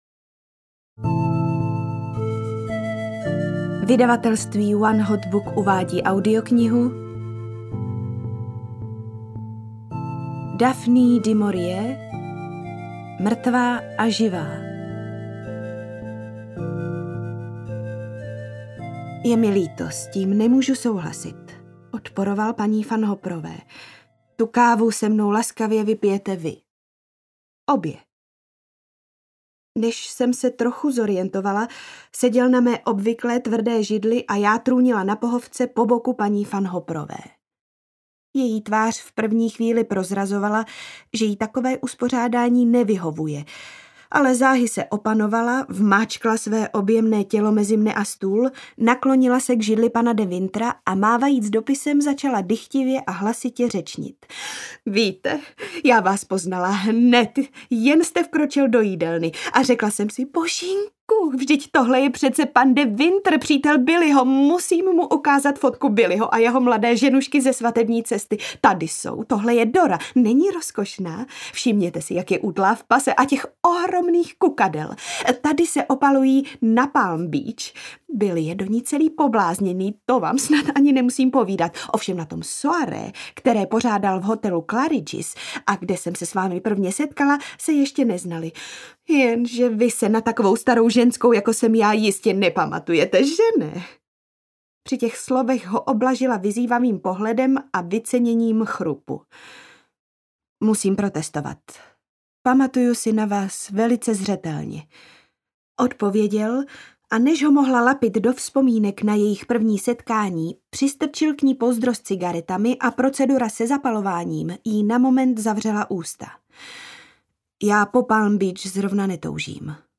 Mrtvá a živá audiokniha
Ukázka z knihy